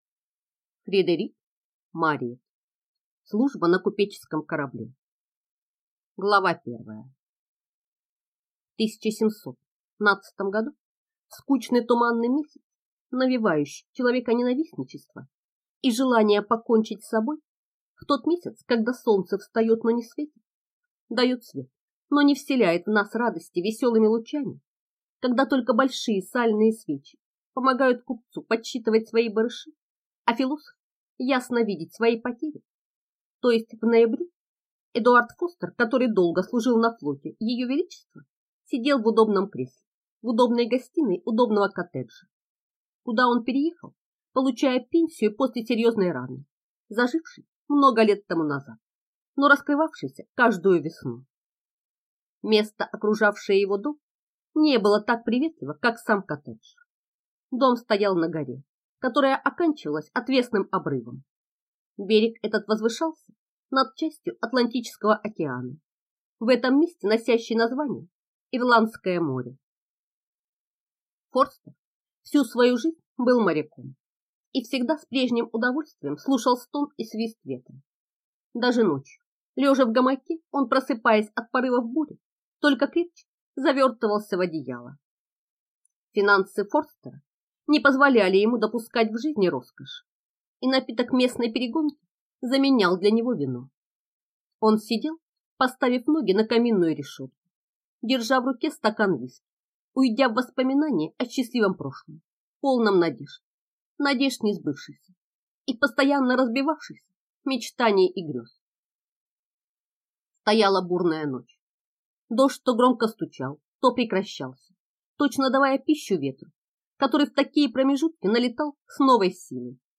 Аудиокнига Служба на купеческом корабле | Библиотека аудиокниг